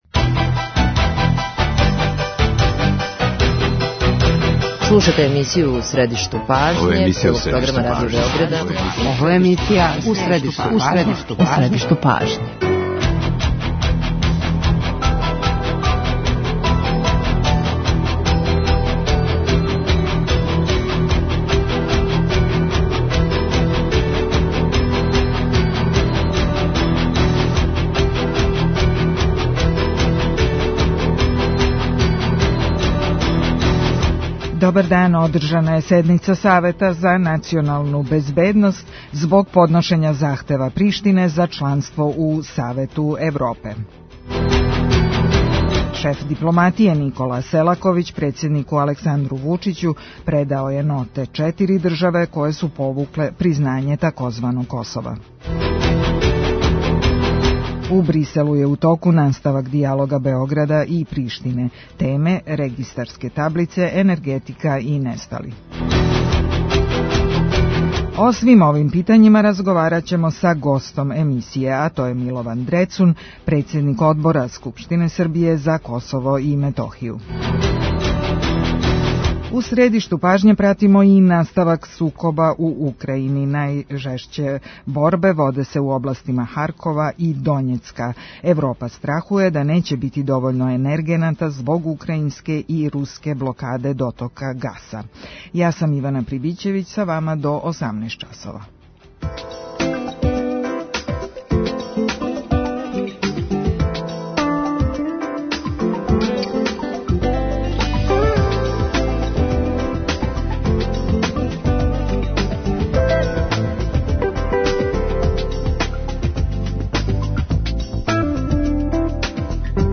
Од успеха преговора, како кажу у Бриселу, зависи и припрема дијалога на највишем нивоу. преузми : 9.93 MB У средишту пажње Autor: Редакција магазинског програма Свакога радног дана емисија "У средишту пажње" доноси интервју са нашим најбољим аналитичарима и коментаторима, политичарима и експертима, друштвеним иноваторима и другим познатим личностима, или личностима које ће убрзо постати познате.
Како све те околности утичу на даље решавање питања Косова и Метохије? Гост емисије је Милован Дрецун, председник Одбора за Косово и Метохију у Скупштини Србије.